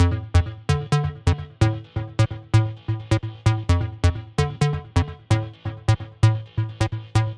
描述：用Fl Studio 11实现的房屋环路 A调或F、D、A调
Tag: 130 bpm House Loops Synth Loops 1.24 MB wav Key : A